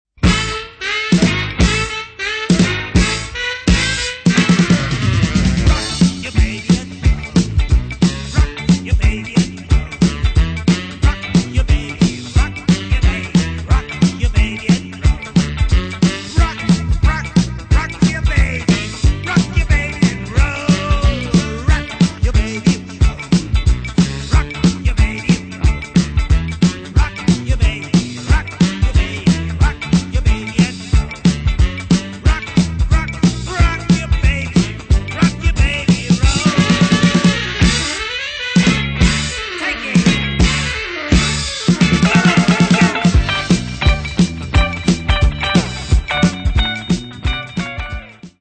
funny fast voc.